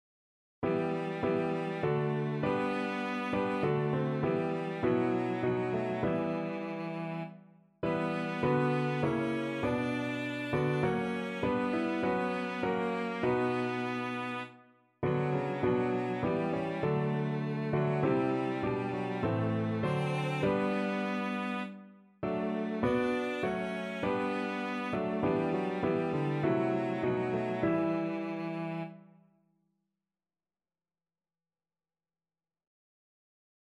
Cello
E minor (Sounding Pitch) (View more E minor Music for Cello )
4/4 (View more 4/4 Music)
Classical (View more Classical Cello Music)